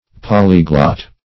Polyglot \Pol"y*glot\, n.
polyglot.mp3